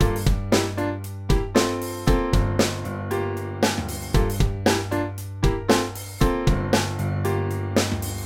vintage-drive-off-1
vintage-drive-off-1.mp3